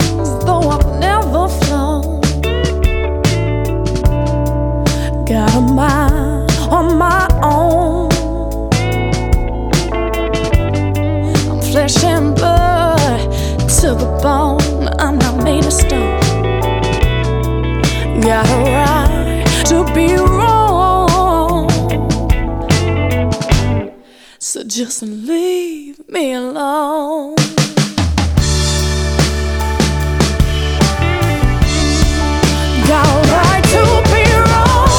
Жанр: Рок / R&B / Альтернатива / Соул